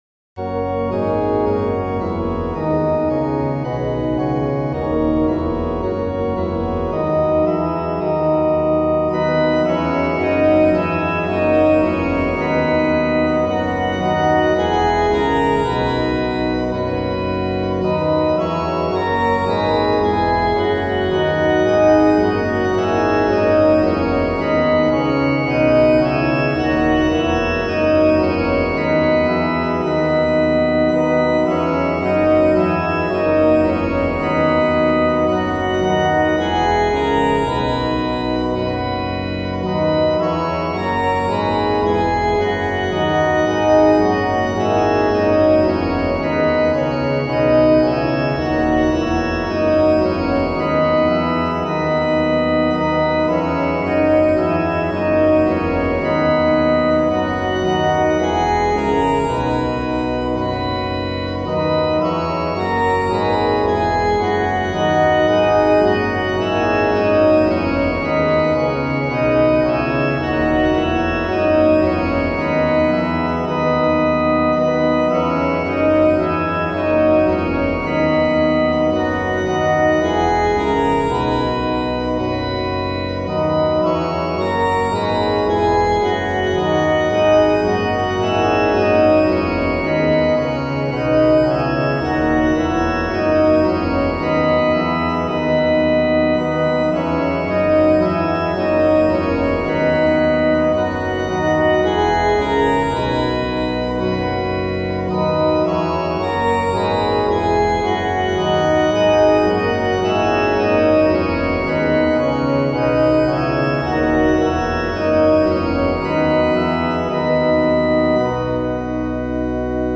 It is set to PUER NOBIS a c16 tune.
It sounds a bit like Yankee Doodle.
Having a lot of traditional hymns to do backings for in CWB II, has meant I have looked at bit harder at getting better organ sounds and I have found a way to improve things somewhat. It’s still only BIAB fake organ though.